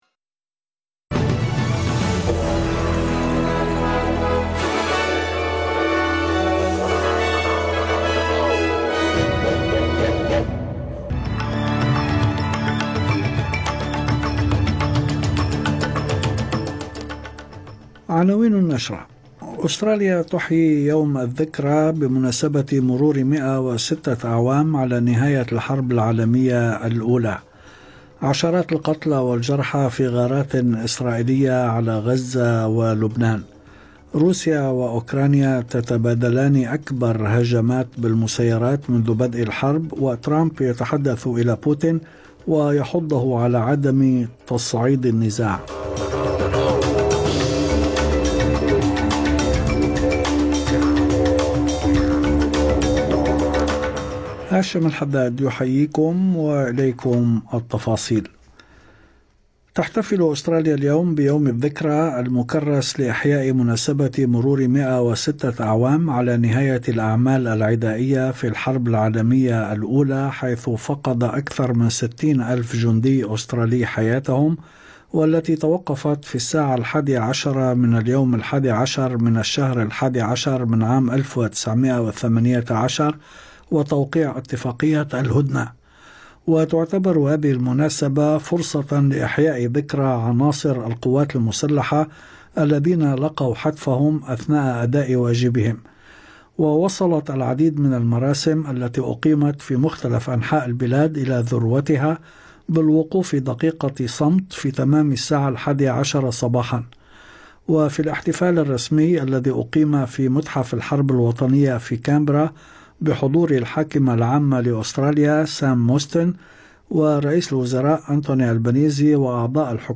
نشرة أخبار المساء 11/11/2024